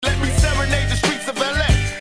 So now that you know how 2Pac says "Streets of L.A." my post will be more fun to read.